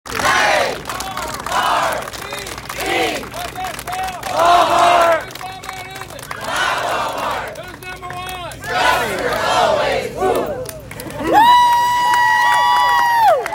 The ribbon cutting concluded with the Wal-Mart chant.
walmart-chant.m4a